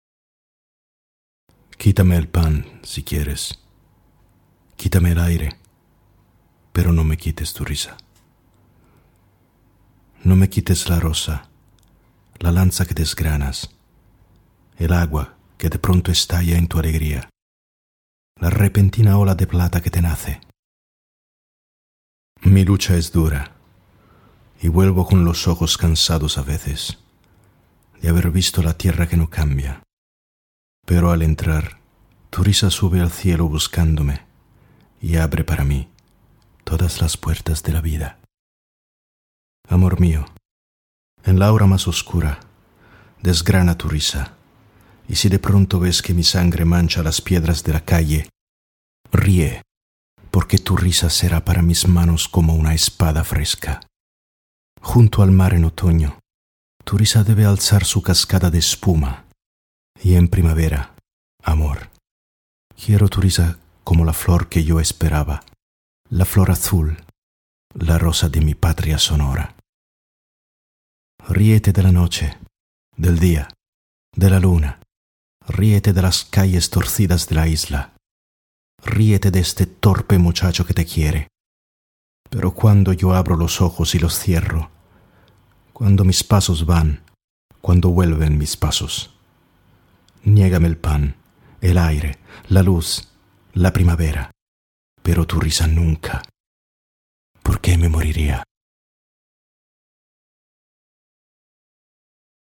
Pido a todos los hispanohablantes que perdonen la pronunciacion...